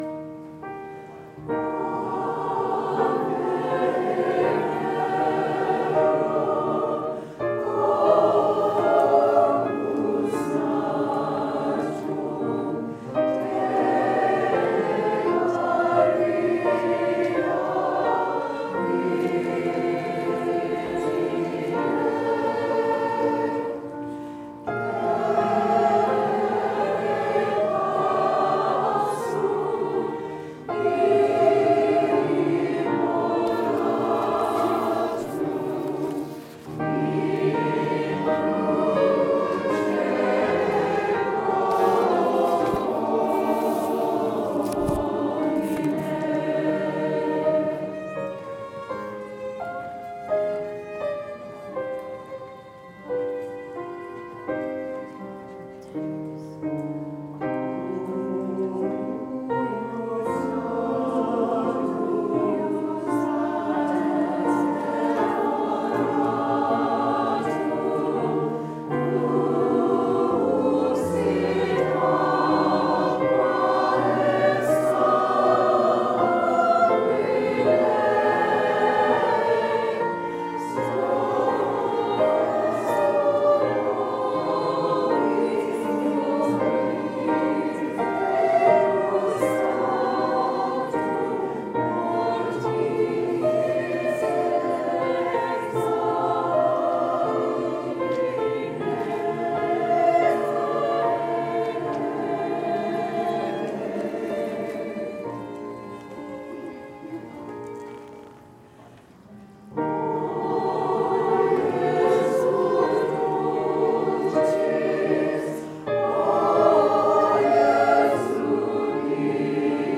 Chrism Mass Diocesan Choir